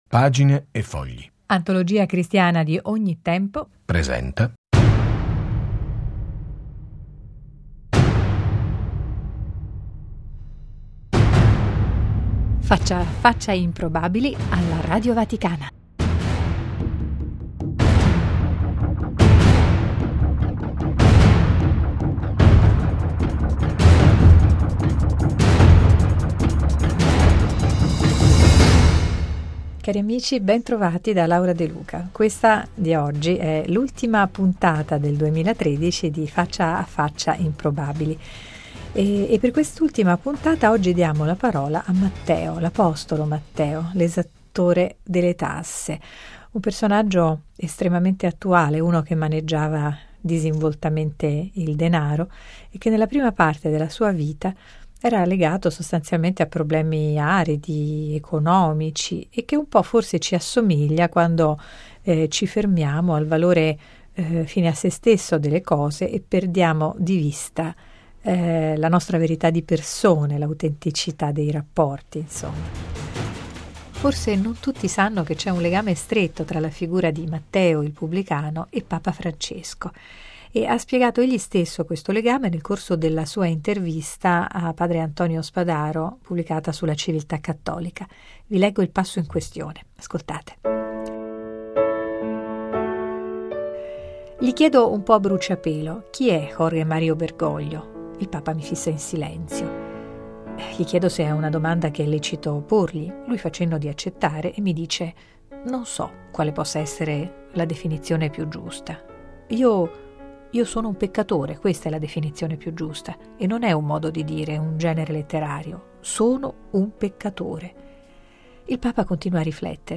La trasmissione odierna si deve alla cortesia di Pino Colizzi , nel ruolo di Matteo, che ha donato alla Radio Vaticana alcuni passi della registrazione originale realizzata nei suoi studi privati.